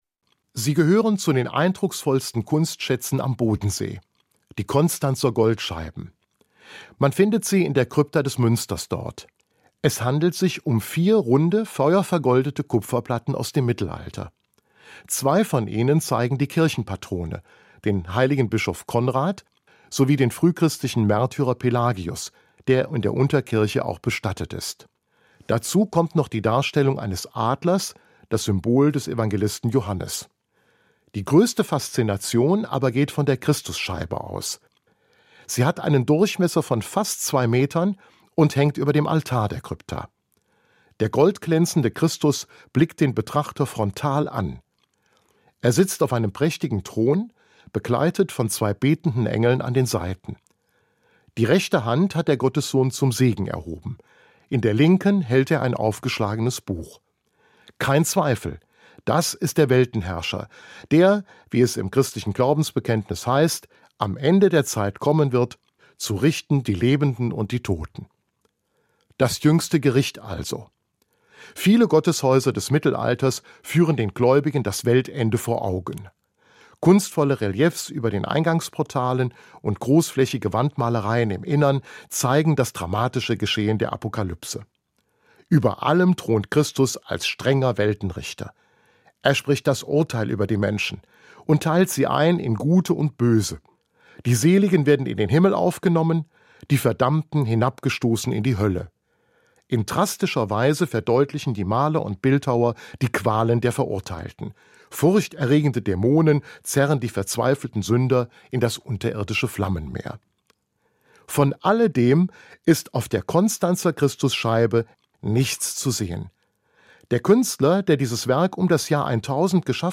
Kirchensendung - Morgenandacht
Hier hören Sie Kurzandachten der evangelischen und katholischen Kirche.